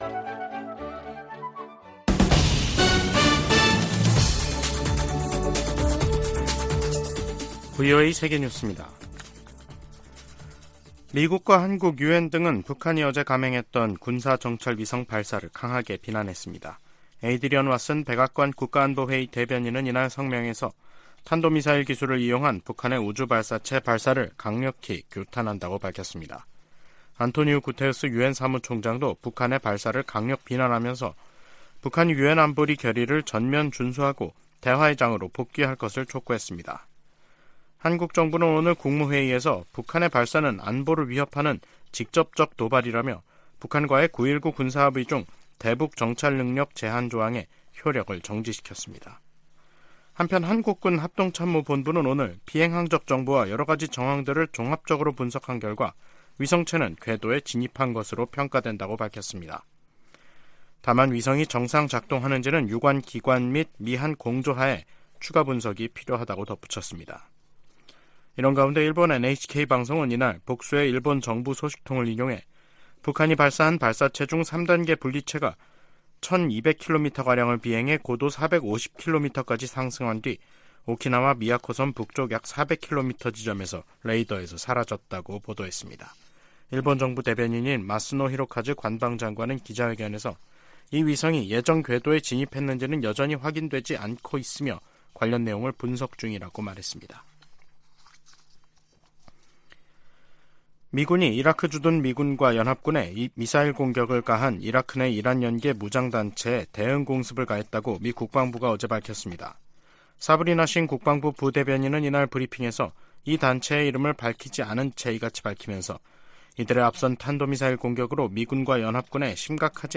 VOA 한국어 간판 뉴스 프로그램 '뉴스 투데이', 2023년 11월 22일 3부 방송입니다. 북한이 군사정찰위성 발사 궤도 진입 성공을 발표하자 미국은 강하게 규탄하고 동맹 방어에 필요한 모든 조치를 취하겠다고 밝혔습니다. 한국 정부는 9.19 남북 군사합의 일부 효력을 정지시켰습니다. 유엔과 유럽연합(EU) 등도 북한의 3차 군사정찰위성 발사가 안보리 결의 위반이라며 강력하게 규탄했습니다.